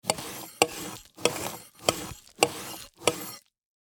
Download Free Cooking Sound Effects | Gfx Sounds
Eggplant-cutting-vegetables-cook.mp3